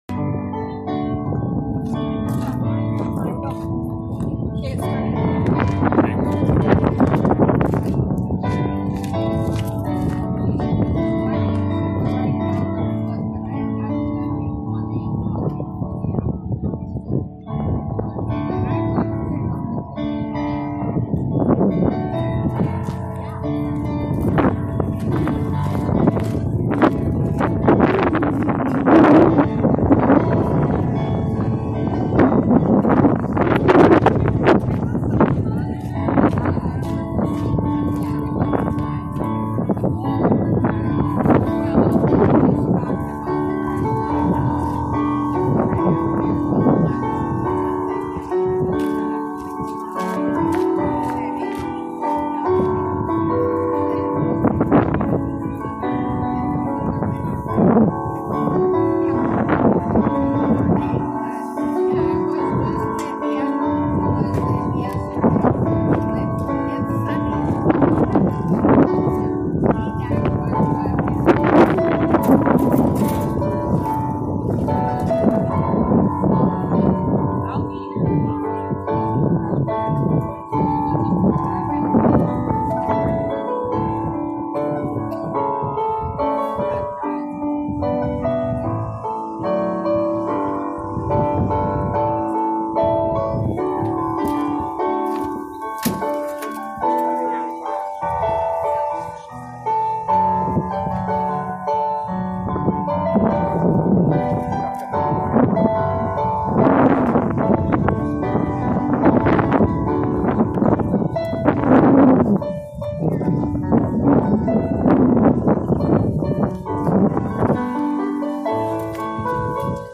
Sunday Worship - Outdoor Service (Note: Poor audio due to wind)
June-14th-Sunday-Service-Grace-Church-of-Erhard.mp3